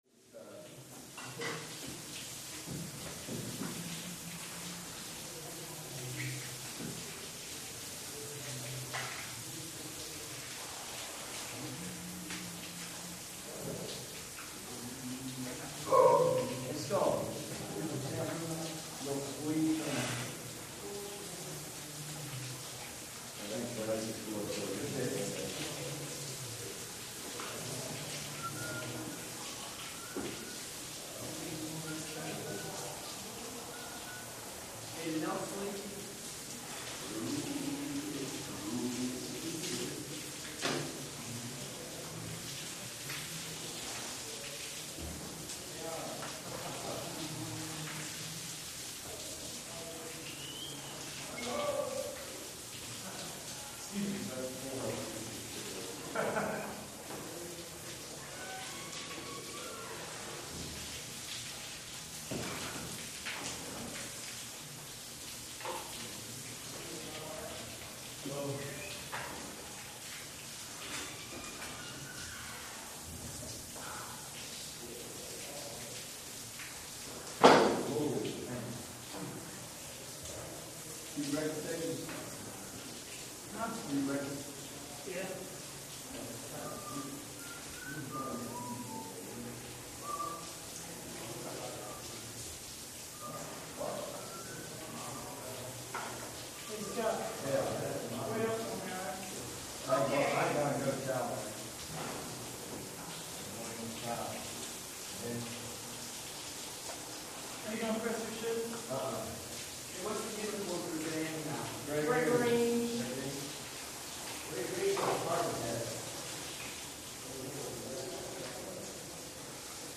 Showers | Sneak On The Lot
Barracks Bathroom Ambience, W Distant Showers, Cu And Distant Voices, Light Activity.